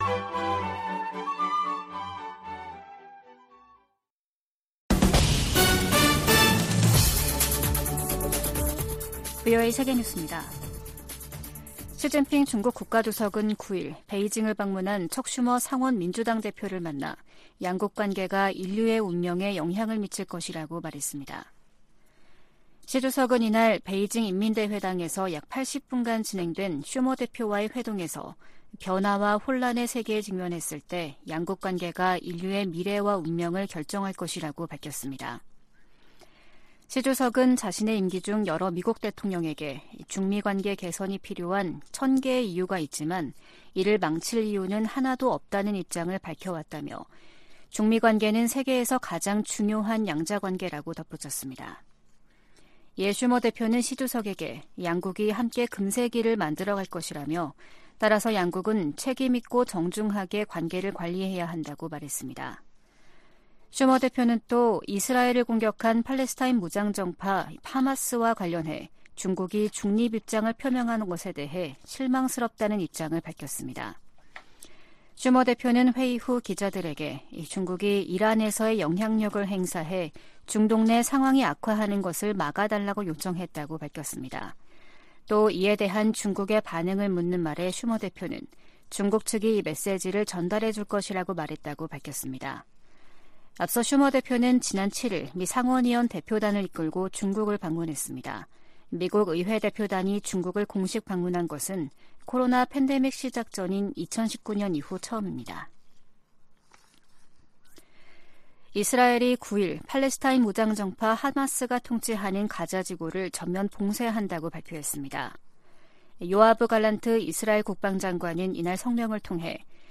VOA 한국어 아침 뉴스 프로그램 '워싱턴 뉴스 광장' 2023년 10월 10일 방송입니다. 미국 정부는 한 때 한국에 동결됐던 이란 자금의 ‘하마스 유입설’을 강하게 부인했습니다. 팔레스타인 무장정파 하마스와 이스라엘의 대규모 무력충돌로 한반도 안보 상황에도 적지 않은 영향이 불가피할 전망입니다. 유럽연합은 러시아로 북한의 대포가 이전되기 시작했다는 보도와 관련해 양국 간 무기 거래 중단을 촉구했습니다.